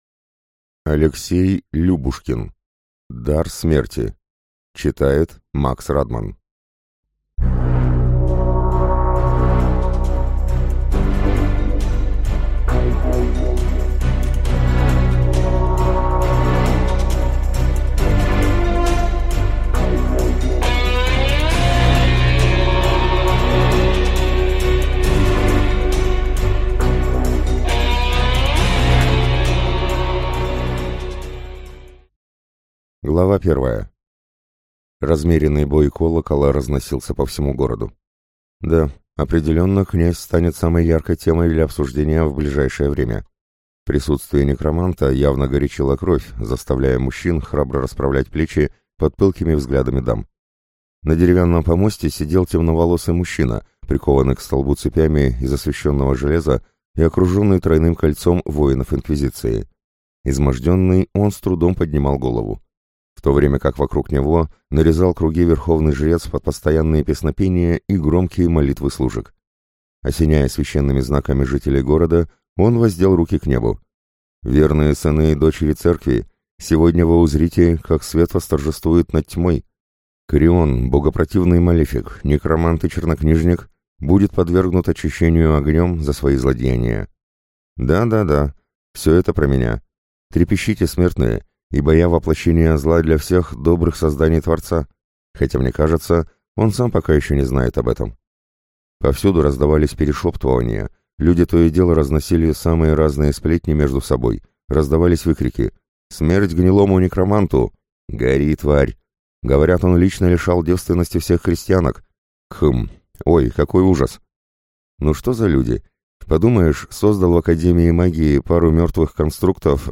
Аудиокнига Дар Смерти | Библиотека аудиокниг
Прослушать и бесплатно скачать фрагмент аудиокниги